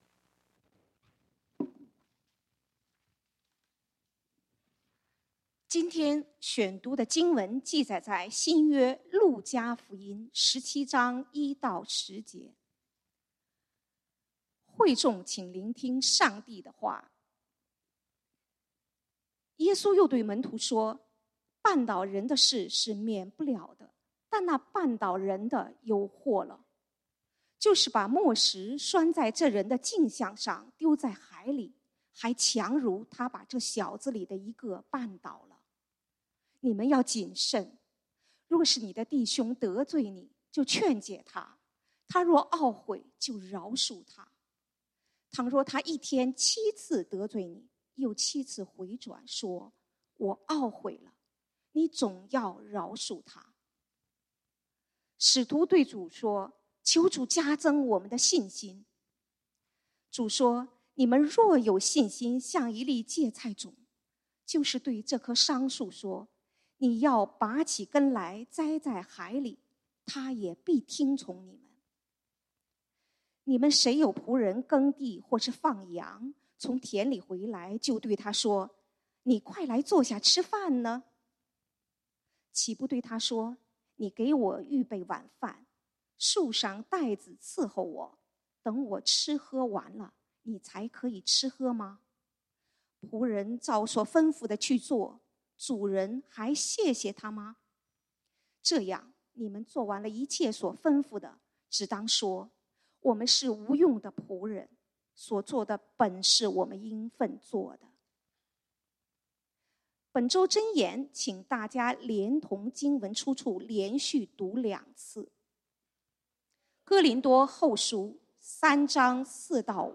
10/2/2022 講道經文：路加福音 Luke 17:1-10 本週箴言：哥林多後書 2 Corinthians 3:4-5 我們在上帝面前，藉着基督才有這樣的信心。